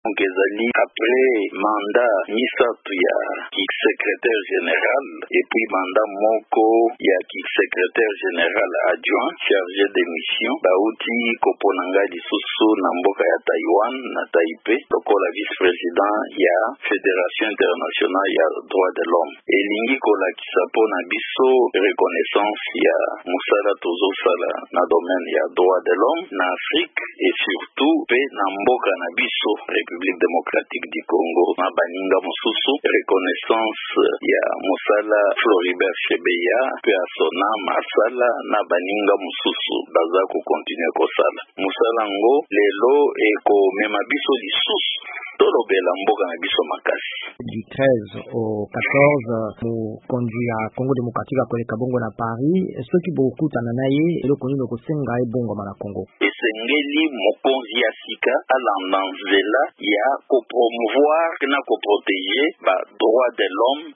VOA Lingala etuni mpo etali mosala akosala.